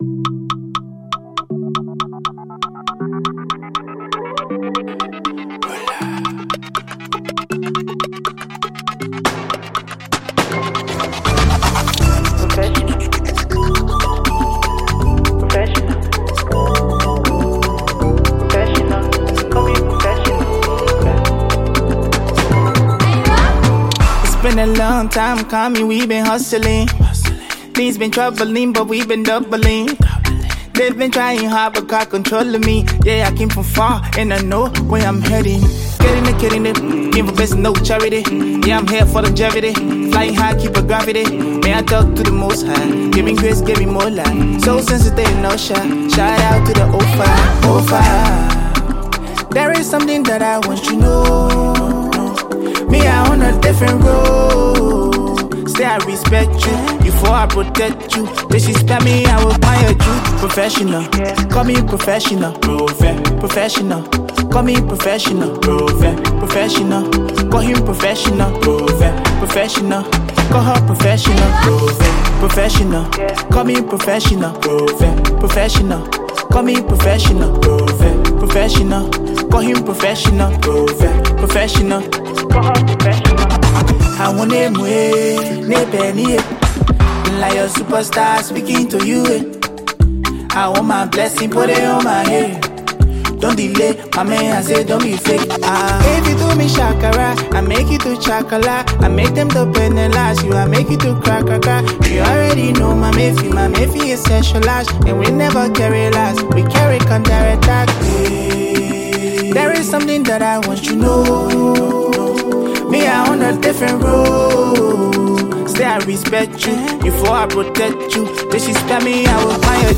Categories: Afro-Beats,Afro-PopAfrobeatsLiberian Music